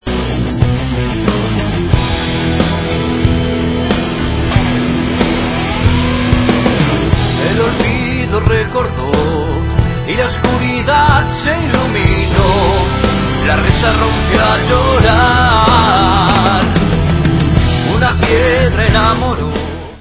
sledovat novinky v kategorii Rock
sledovat novinky v oddělení Heavy Metal